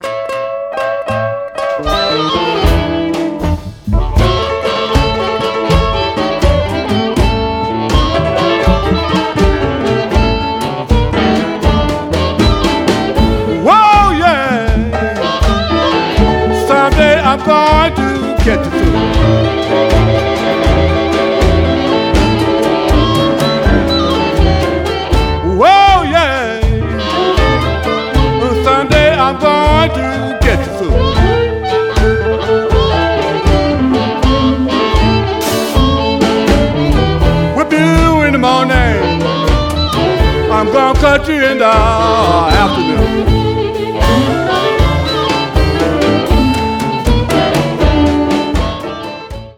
traditional blues